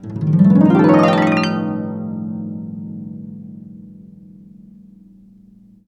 HARP BWT ARP.wav